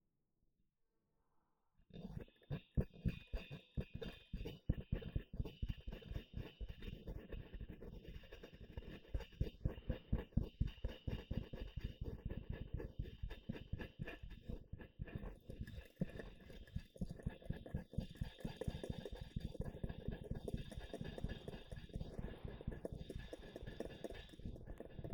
I am more concerned with impercetible sounds in my recordings and this was my focus during the workshop, although I did record some sounds in the stairwell and then some smaller sounds.
ste-037-noise-reduction-echo-phaser.wav